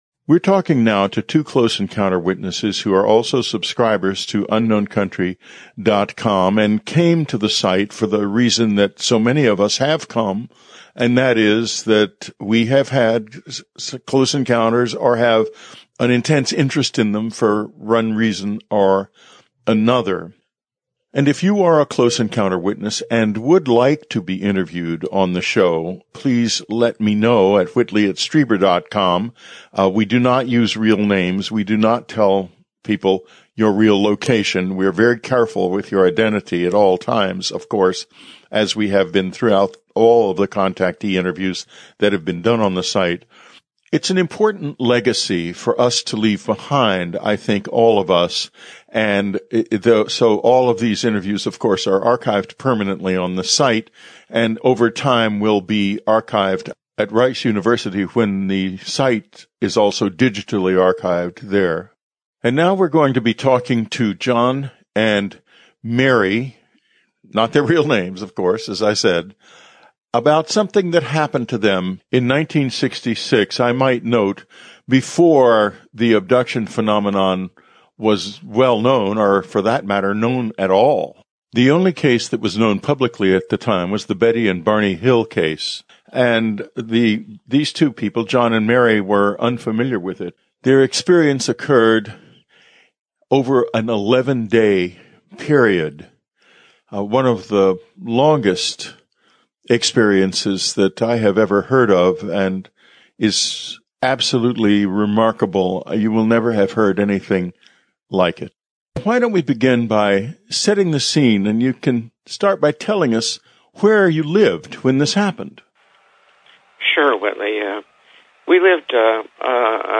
We have just added an interview to our Contactee Section with a couple who were living on Puget Sound in 1966 when a UFO dropped down behind their home, resulting in 11 days of close encounters, including a vividly remembered approach of non-humans into their living room and an extensive abduction experience that involved them and their neighbors and apparently many other people in the area.